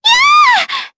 Talisha-Vox_Damage_Jp_03.wav